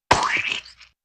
splat5.ogg